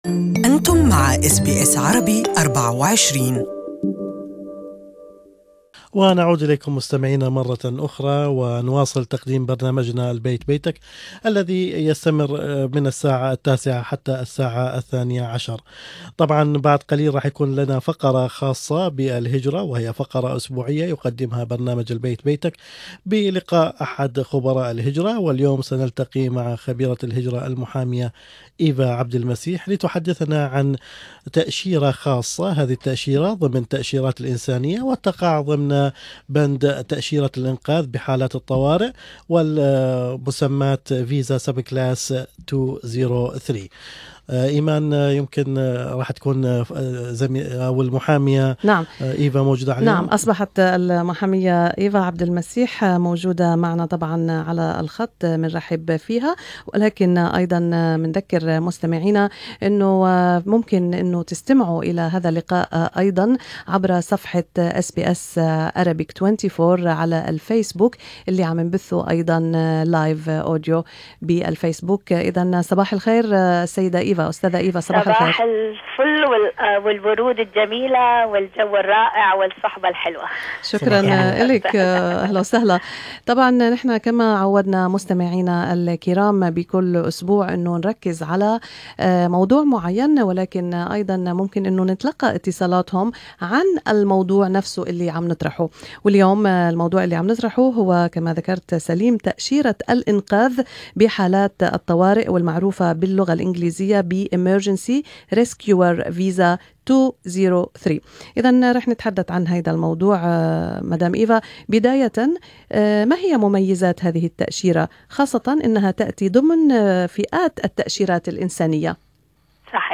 اللقاء الأسبوعي حول الهجرة.
كل التفاصيل بالإضافة إلى أسئلة المستمعين والأجوبة عليها، موجودة في الرابط الصوتي تحت الصورة.